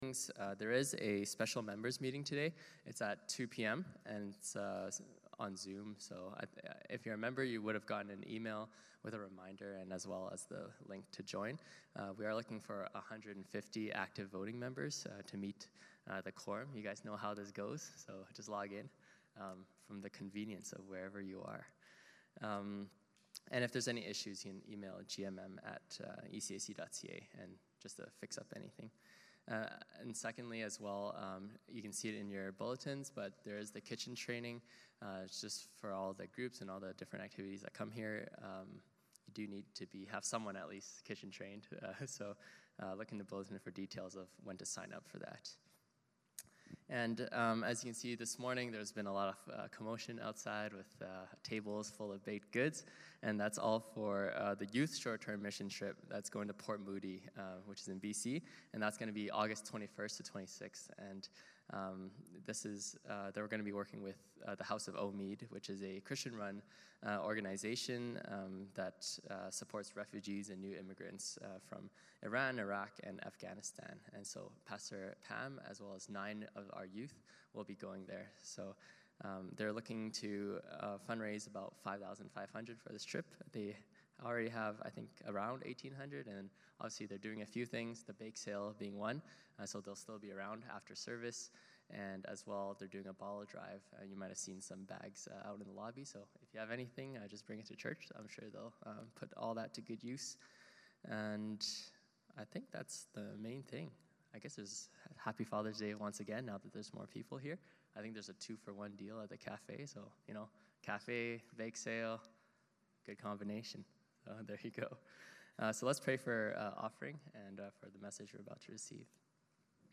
Passage: Deuteronomy 14:22-29; Luke 11:37-44 (ESV) Service Type: Sunday Morning Service « In Christ